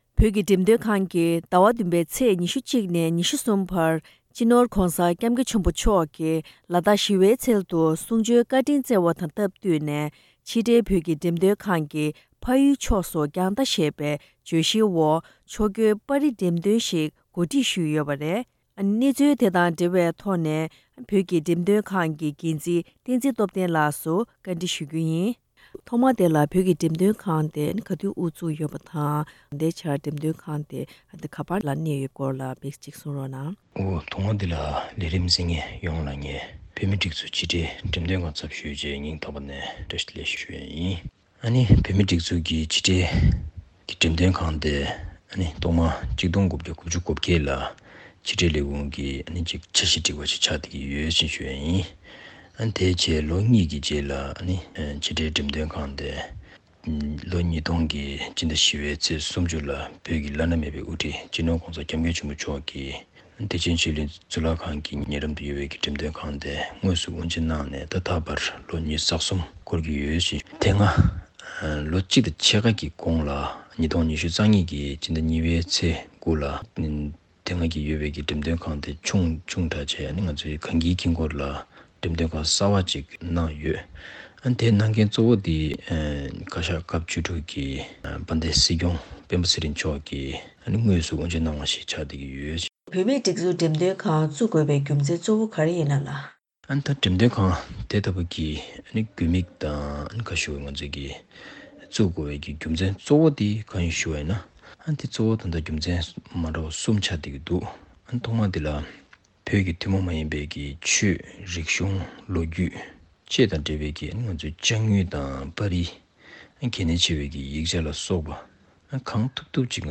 བཀའ་འདྲི་བཞུ་བ་ཞིག་གསན་རོགས་གནང་།